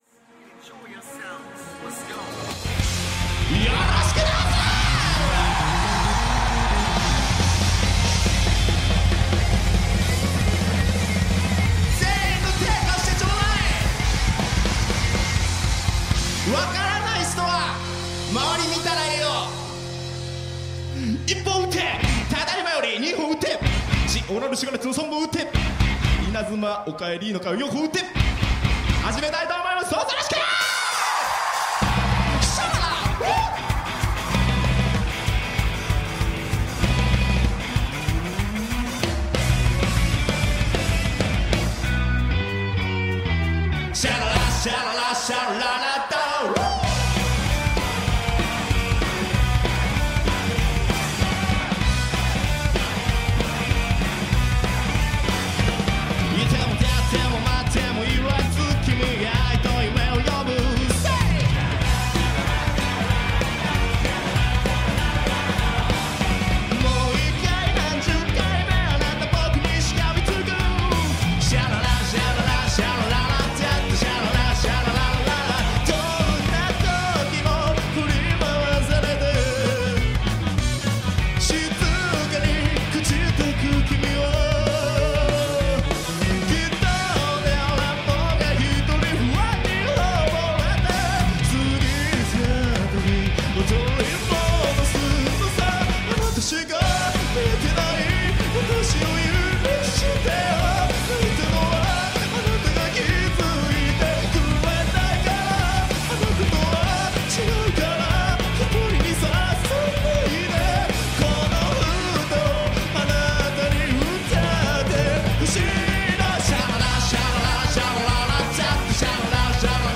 Japanese Alternative/Indie band
teensy dash of J-Pop.